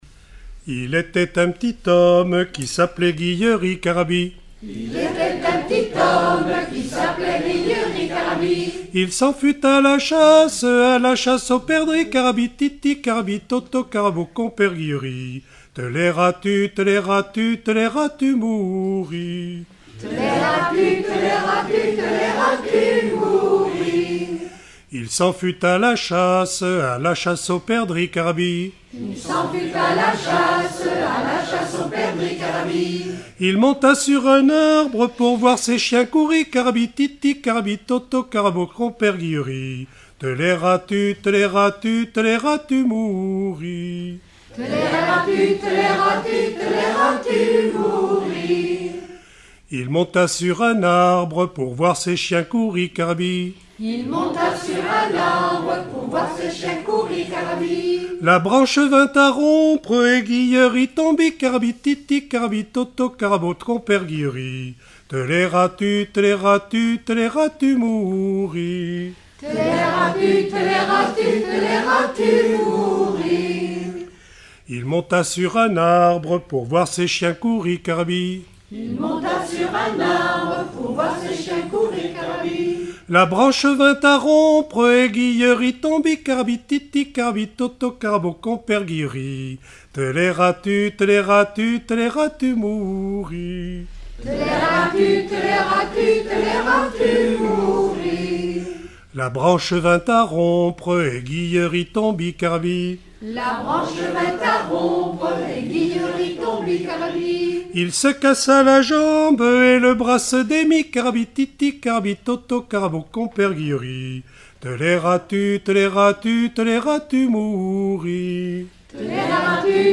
Enfantines - rondes et jeux
Genre laisse
Répertoire de chansons populaires et traditionnelles
Pièce musicale inédite